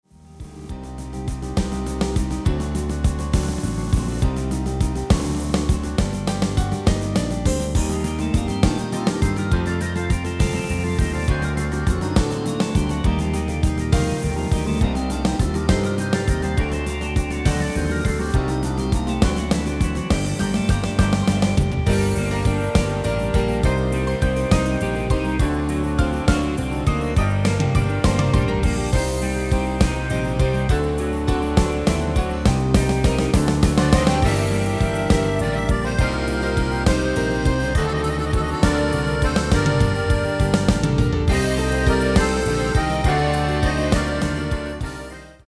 MP3-orkestband Euro 5.75